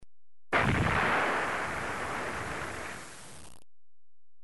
دانلود صدای بمب و موشک 9 از ساعد نیوز با لینک مستقیم و کیفیت بالا
جلوه های صوتی